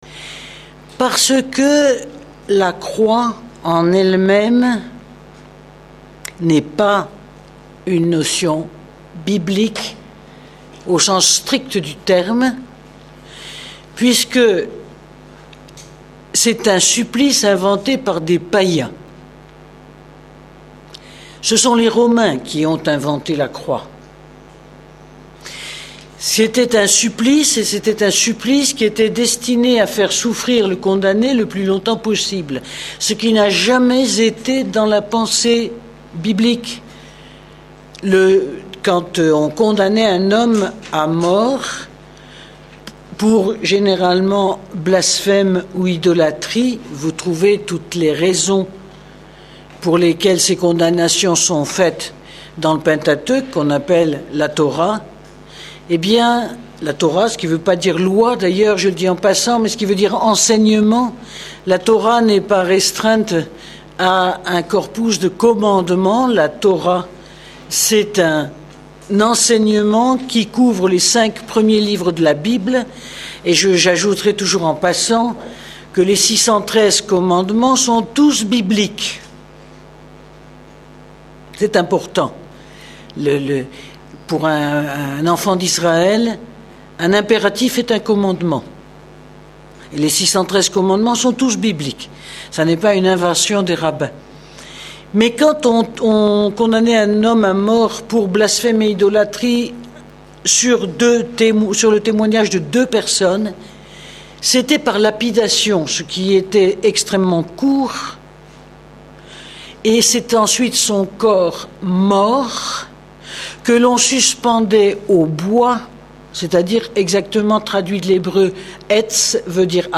Un enseignement passionnant qui replonge aux racines de notre foi.
Enregistré lors de la session de Lourdes 2009.